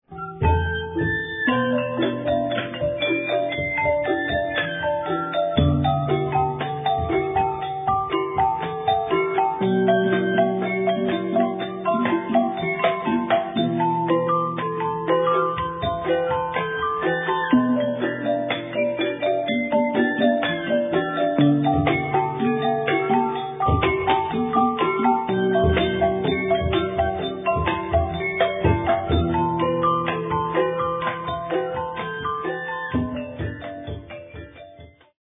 gamelan prawa